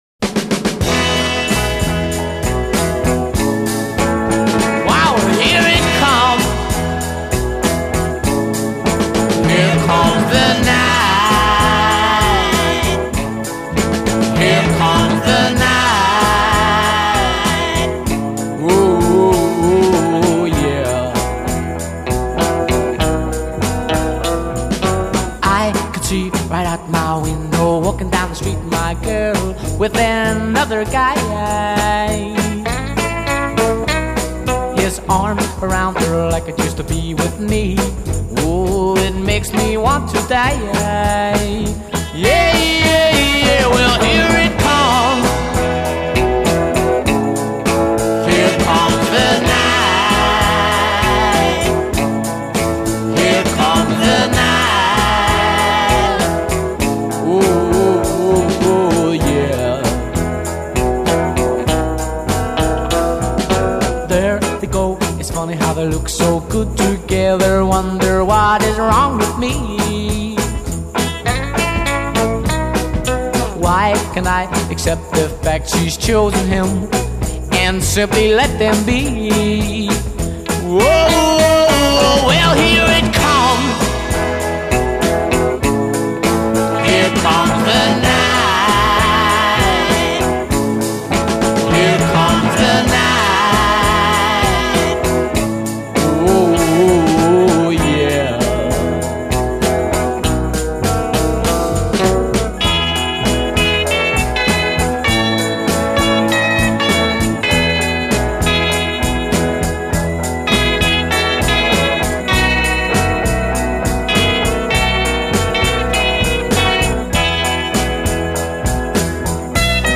B Refrain : 16+ repeat hook and fade